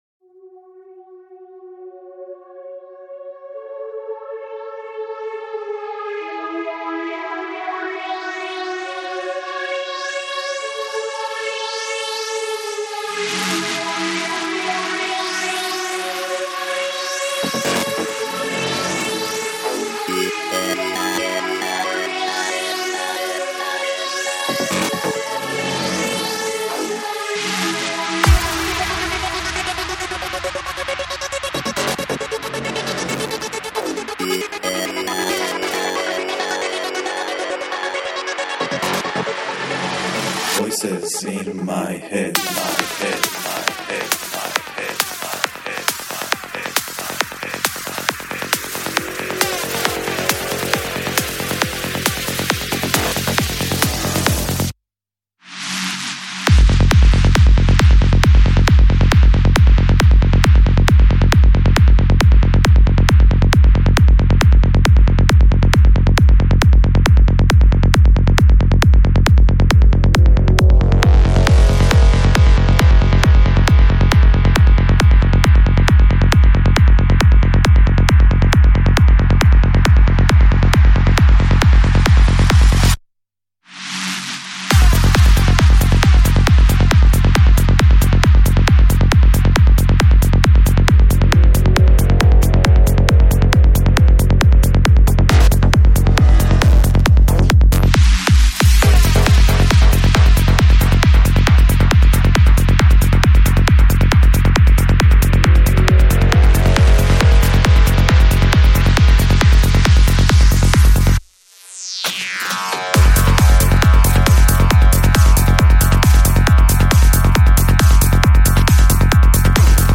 Жанр: Psychedelic
Альбом: Psy-Trance